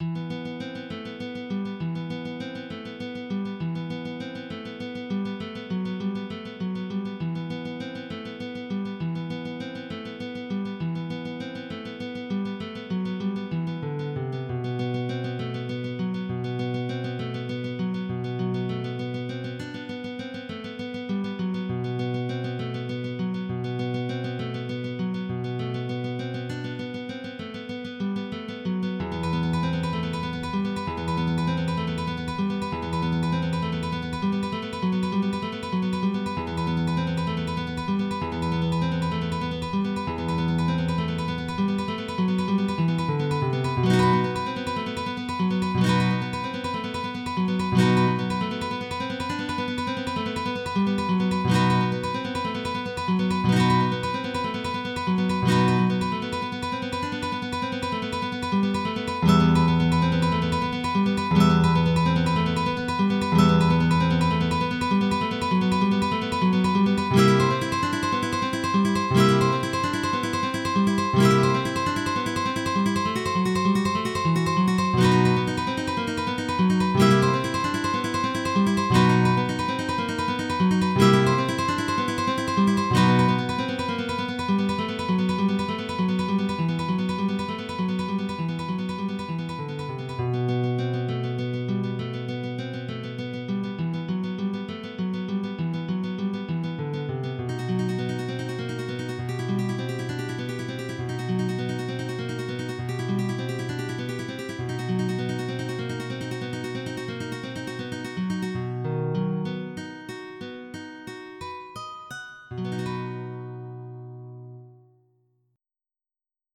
MIDI Music File
Type General MIDI